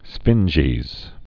(sfĭnjēz)